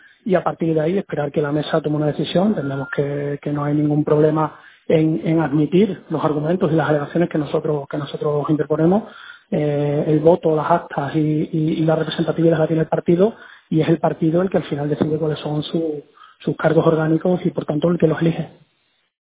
En declaraciones exclusivas a COPE, el coordinador provincial por Badajoz y futuro alcalde de la Capital Pacense, Ignacio Gragera, asegura que Ciudadanos ha presentado en la cámara regional un escrito de respuesta al presentado por los diputados disidentes con la decisión del comité y espera que se imponga la razón.